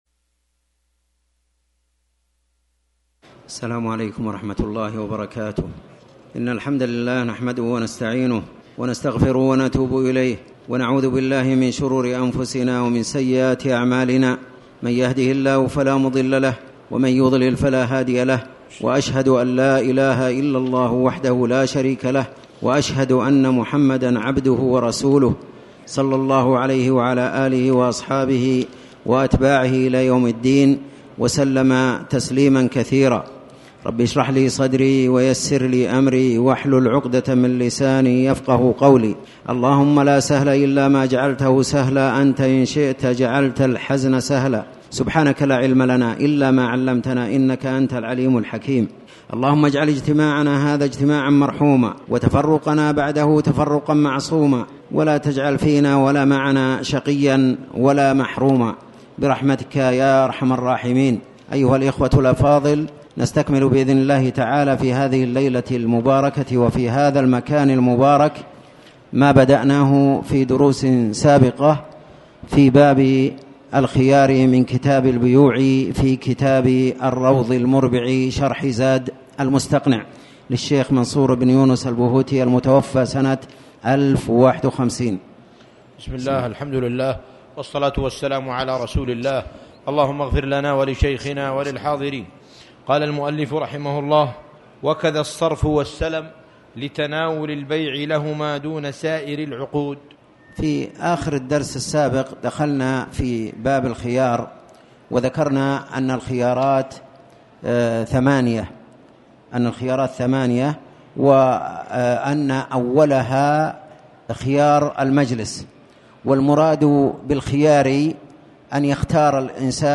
تاريخ النشر ٢٤ رجب ١٤٣٩ هـ المكان: المسجد الحرام الشيخ